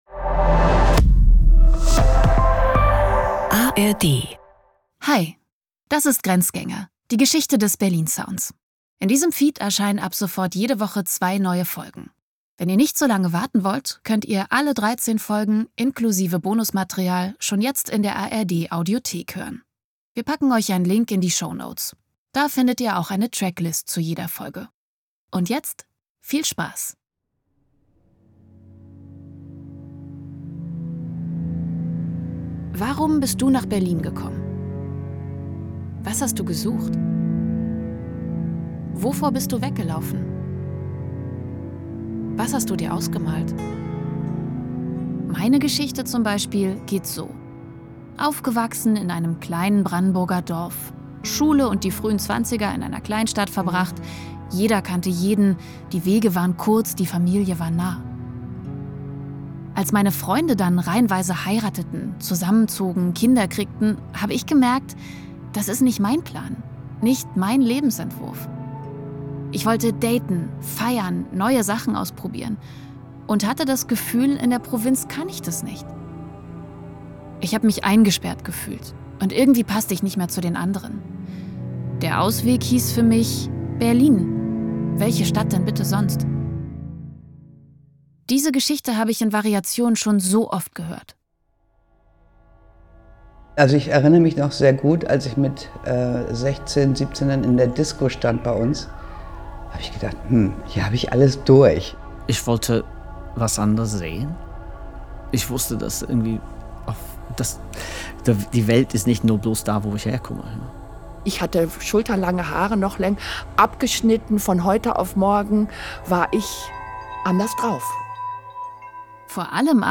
die hier zum ersten Mal in einem Interview zu hören ist.